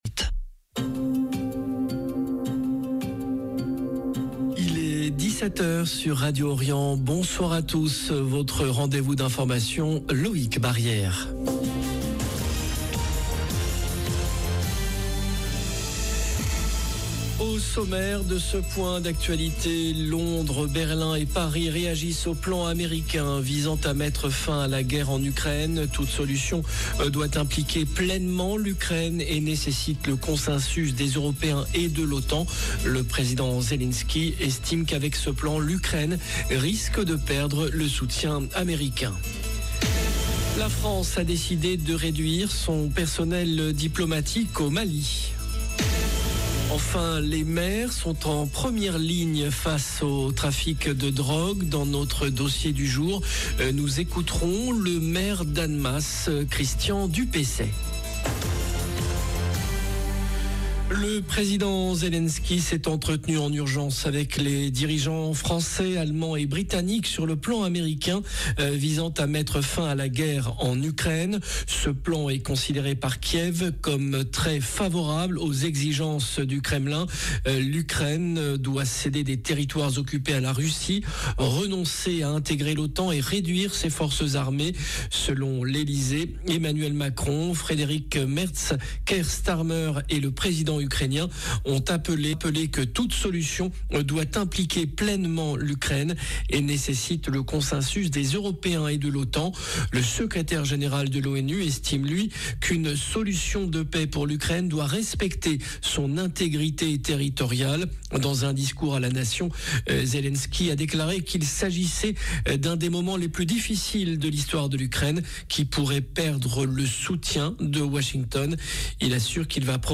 JOURNAL DE 17H
La France a décidé de réduire son personnel diplomatique au Mali. Enfin les maires sont en première ligne face au trafic de drogue. Dans notre dossier du jour, nous écouterons le maire d’Annemasse Christian Dupessey 0:00 7 min 53 sec